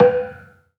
HSS-Gamelan-1
Kenong-dampend-B3-f.wav